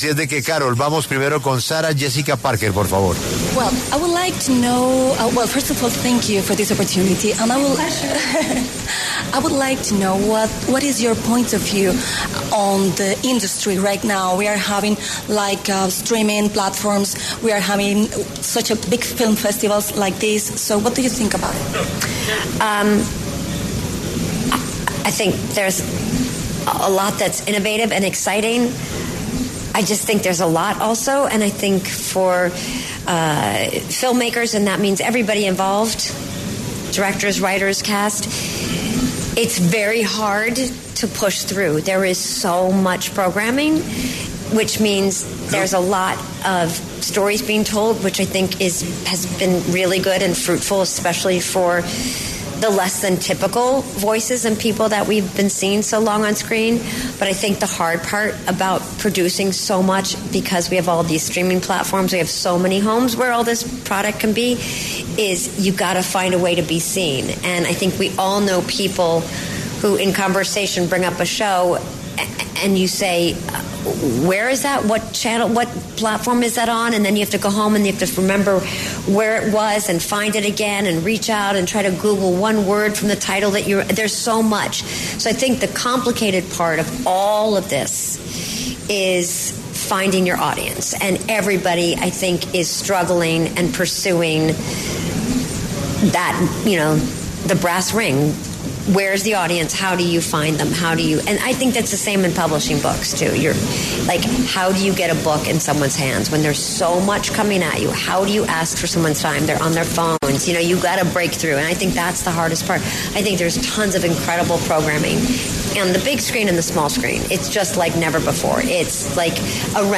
La actriz Sarah-Jessica Parker, conocida por dar vida a Carrie Bradshaw en la serie ‘Sex and the City’, habla desde el Festival Internacional de Cine del Mar Rojo en Yeda, Arabia Saudita.
Desde el Festival Internacional de Cine del Mar Rojo en Yeda, Arabia Saudita, La W conversó con la actriz Sarah-Jessica Parker, conocida por dar vida a Carrie Bradshaw en la serie ‘Sex and the City’.